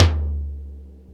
TOM XC.TOM05.wav